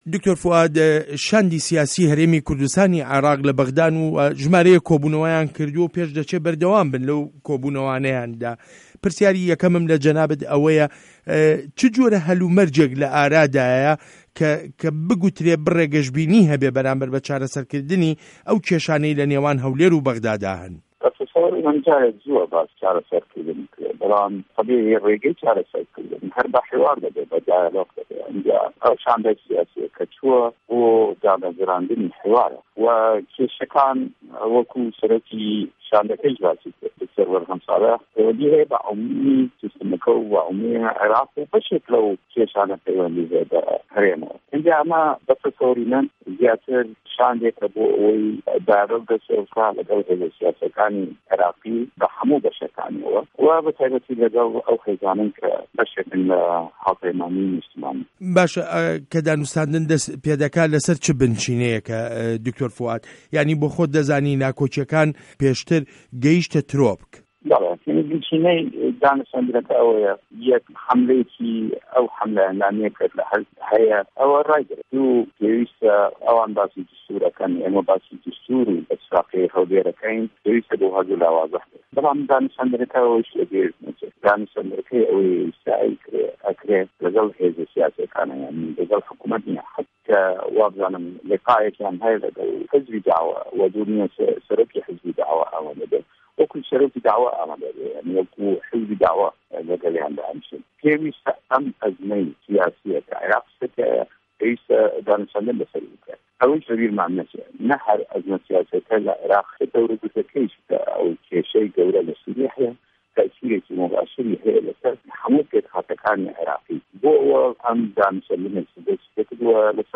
وتووێژ له‌گه‌ڵ دکتۆر فوئاد حسێن